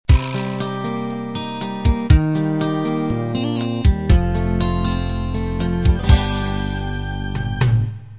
two nice, versatile 8 beat pop/rock/folk/country styles.